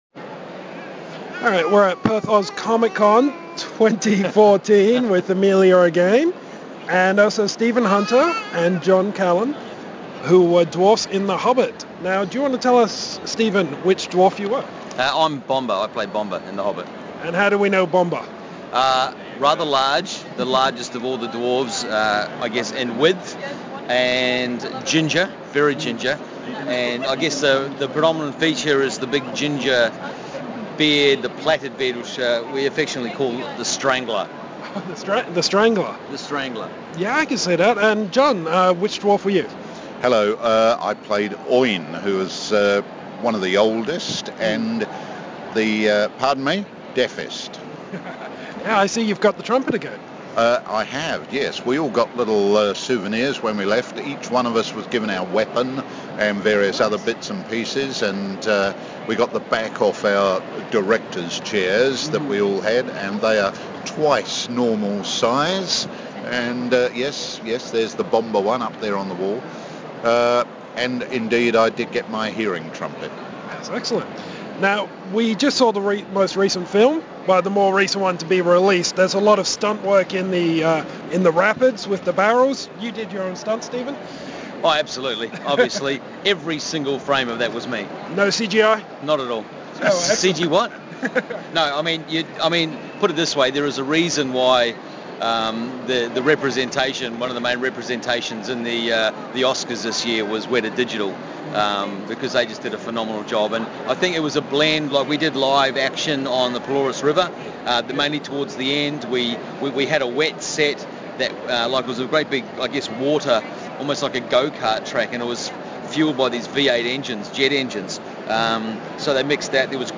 Exclusive Interview with John Callen and Stephen Hunter from ‘The Hobbit’!